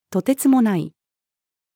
途轍もない-female.mp3